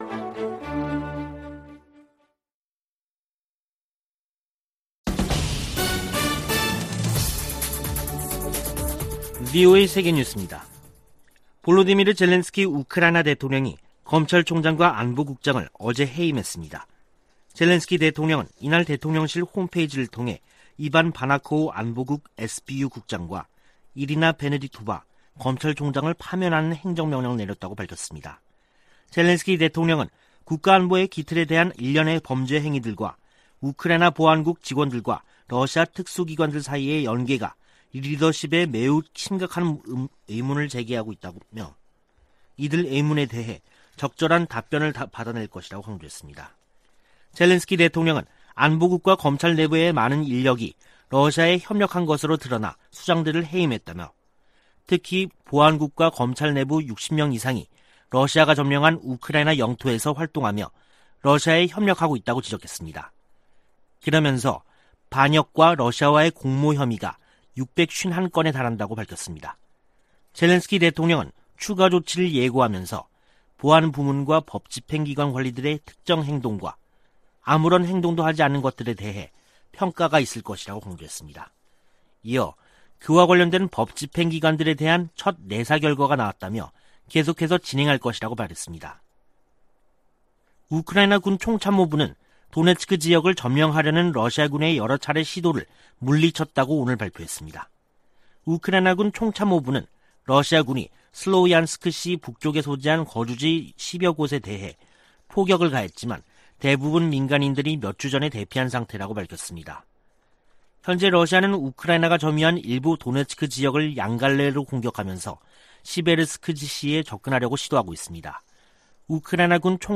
VOA 한국어 간판 뉴스 프로그램 '뉴스 투데이', 2022년 7월 18일 3부 방송입니다. 미 국무부 고위 관리가 정보기술(IT) 분야에 위장 취업한 북한인들과 제3국인들이 제기하는 문제와 위험성을 경고했습니다. 워싱턴 전문가들은 북한의 제재 회피를 방조하는 중국과 러시아를 제재할 필요가 있다고 강조했습니다. 주한미군 규모를 현 수준으로 유지하는 새 회계연도 국방수권법안을 미 하원이 최종 의결했습니다.